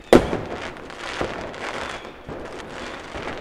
fireworksExplosion.wav